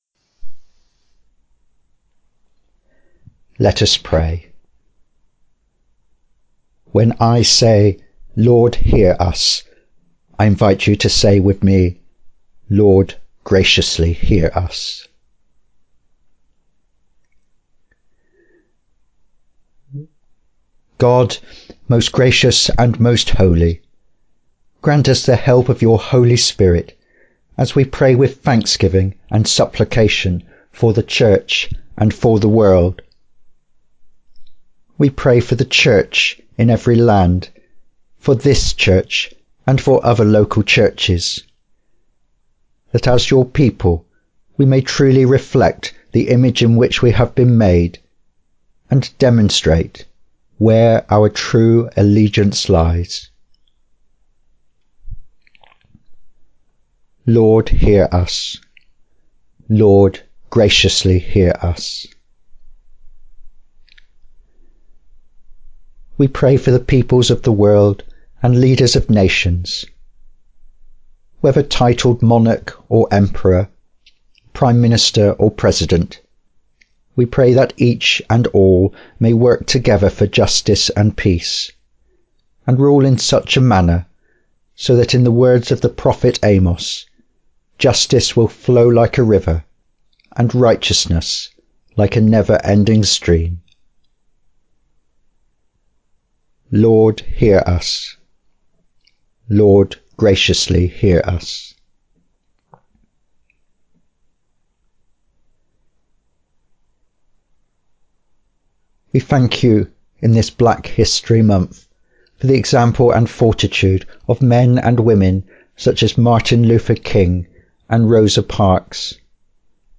Welcome & Call to Worship